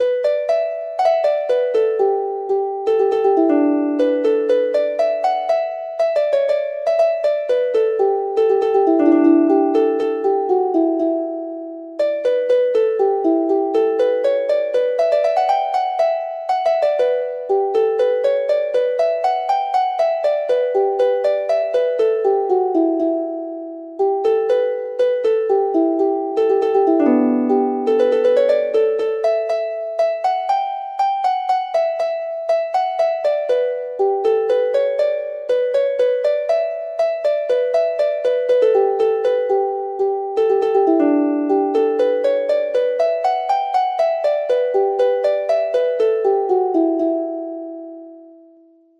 Harp version
Traditional Harp